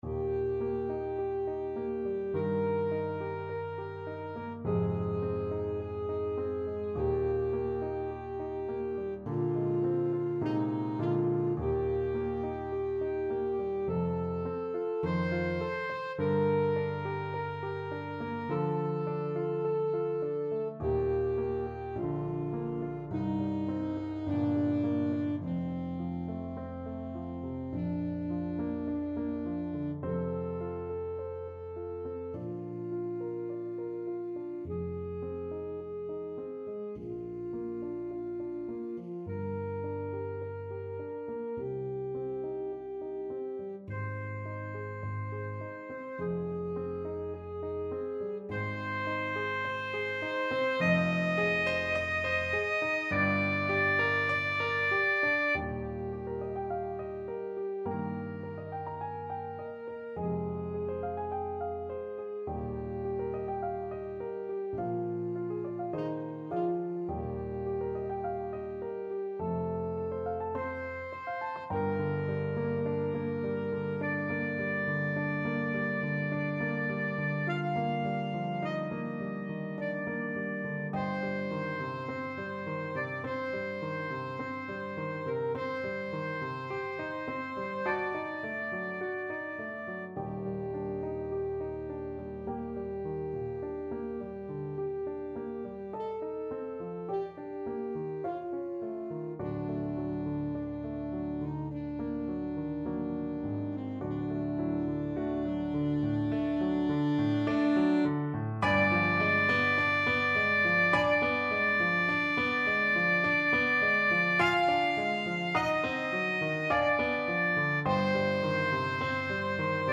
Classical Puccini, Giacomo Intermezzo from Suor Angelica Alto Saxophone version
Alto Saxophone
Eb major (Sounding Pitch) C major (Alto Saxophone in Eb) (View more Eb major Music for Saxophone )
Andante molto sostenuto =58 =52
4/4 (View more 4/4 Music)
Classical (View more Classical Saxophone Music)
puccini_suor_angelica_ASAX.mp3